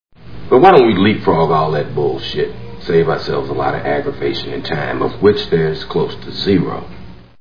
Snakes on a Plane Movie Sound Bites